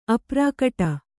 ♪ aprākaṭa